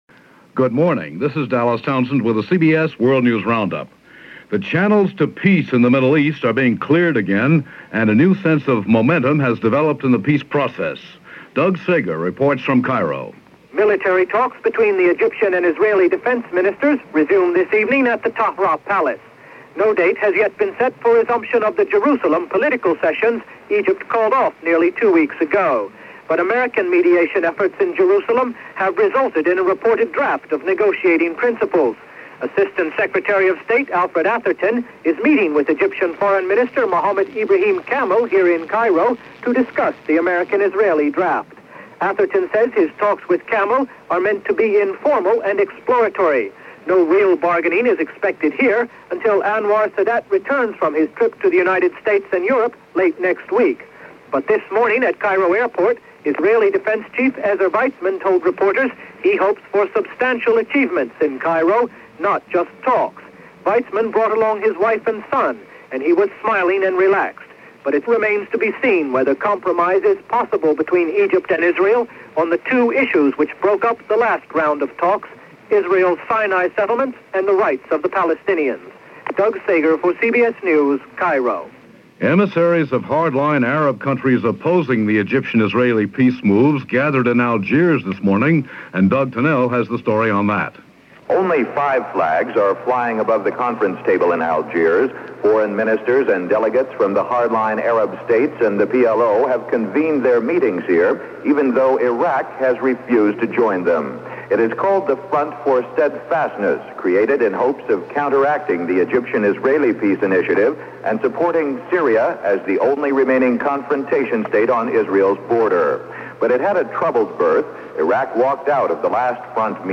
And that’s just a small portion of what went on this January 31, 1978 – as presented by The CBS World News Roundup.